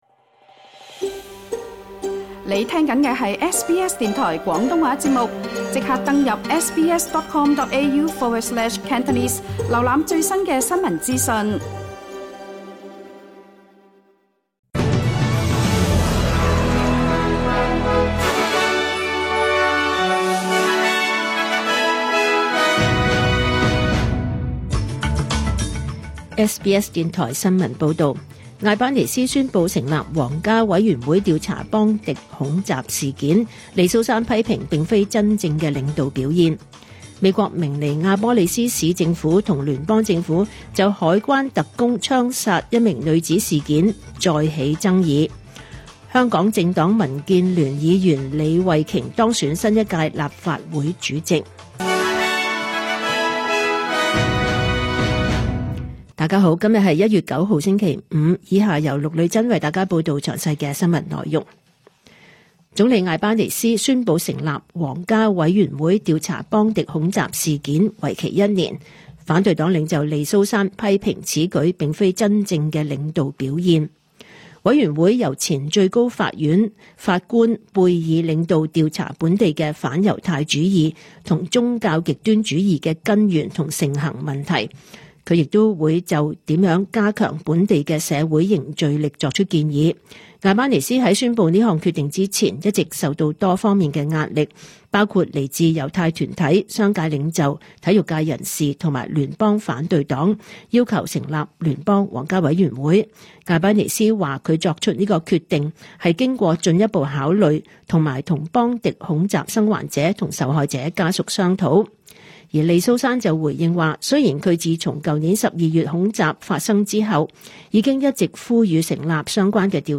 2026 年 1 月 9 日 SBS 廣東話節目詳盡早晨新聞報道。